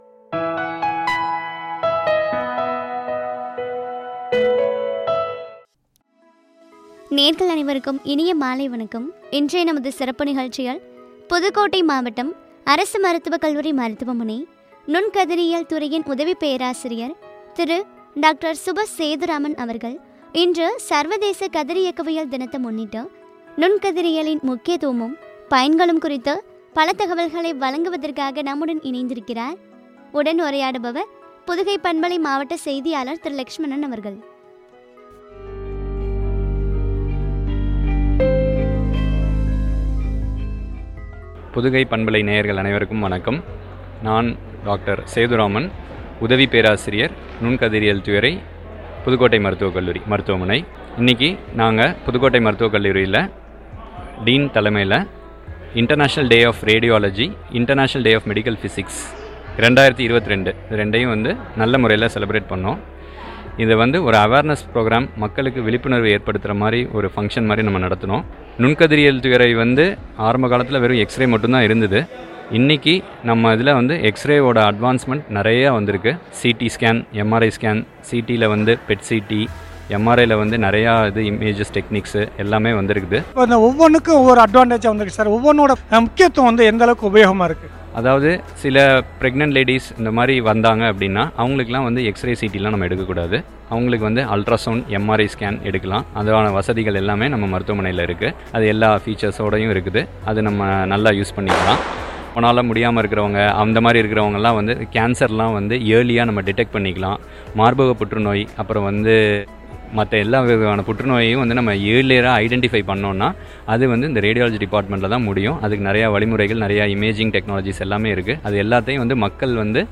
நுண்கதிரியியலின் முக்கியத்துவமும் பயன்களும், பற்றிய உரையாடல்.